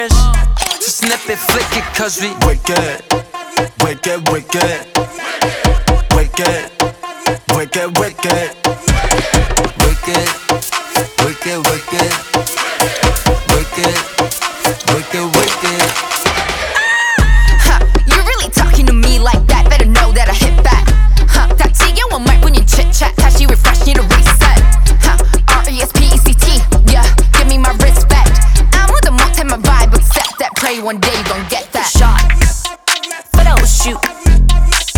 K-Pop
Жанр: Поп музыка